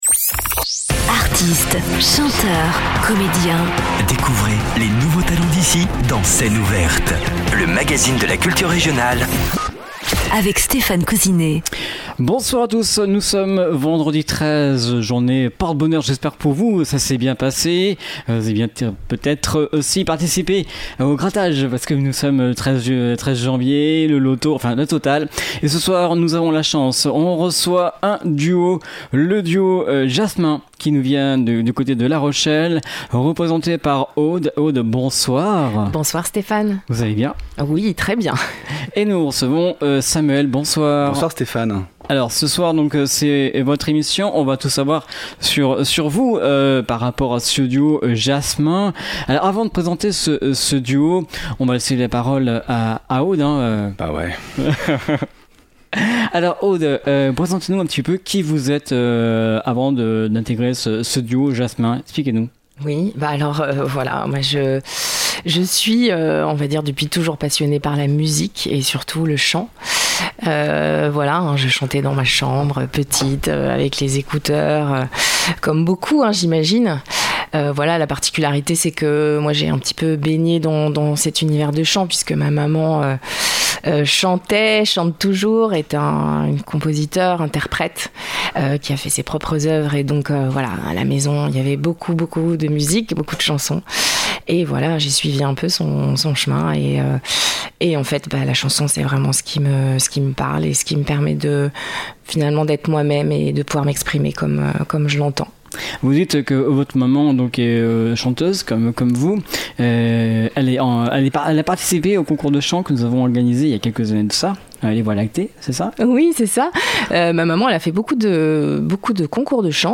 musique vivante